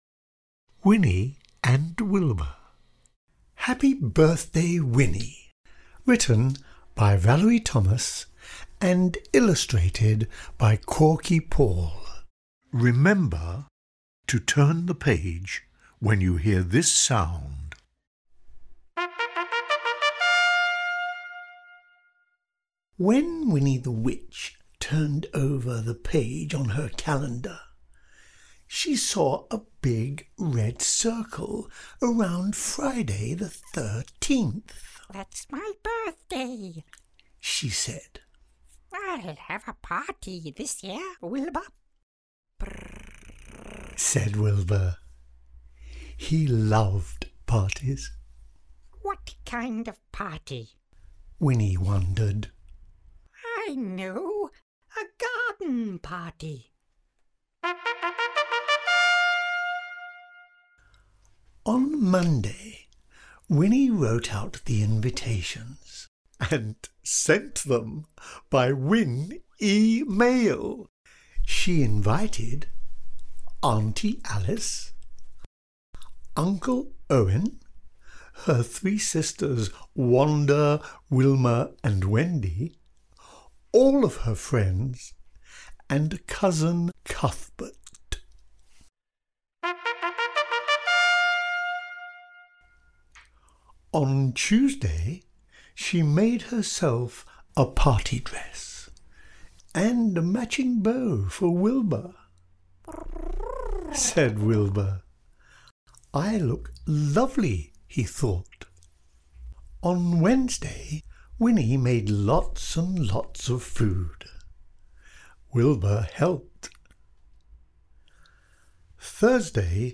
• An audio version of the story with page turn signals